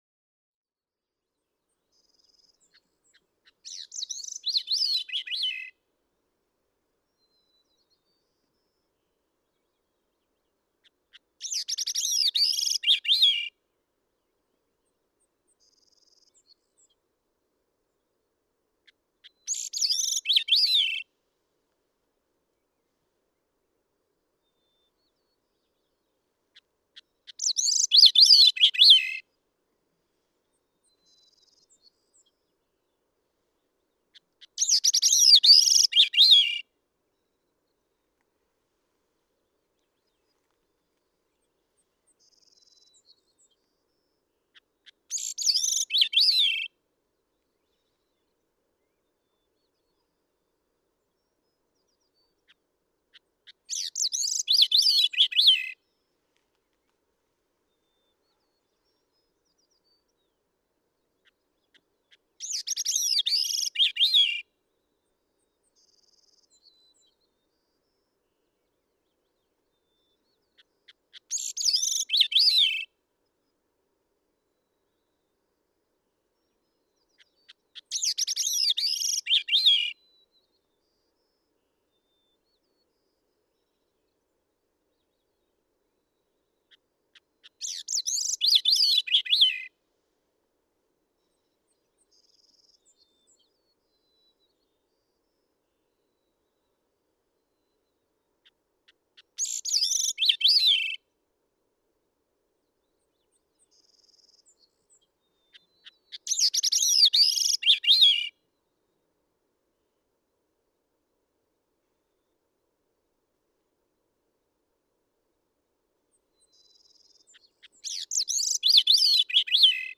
Gray-cheeked thrush
Thrushes are some of the finest songsters.
Denali Highway, Alaska.
661_Gray-cheeked_Thrush.mp3